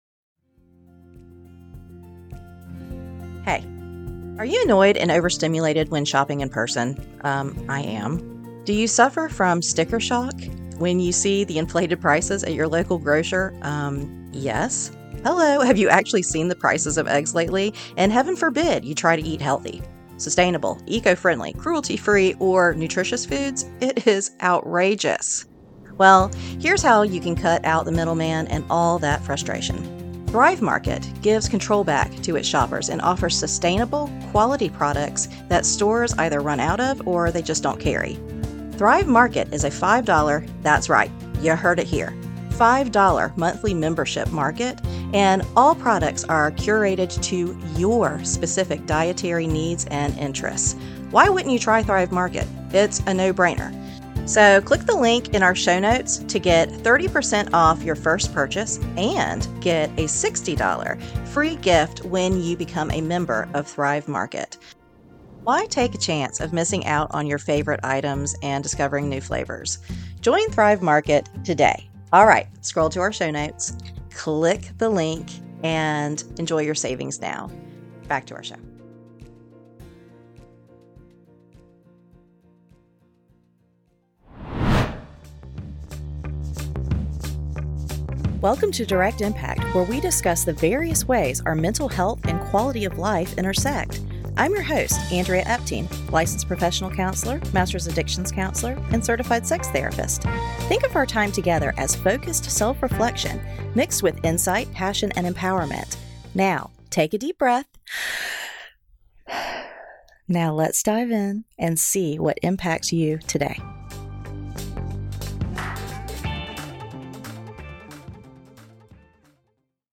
Candid Conversation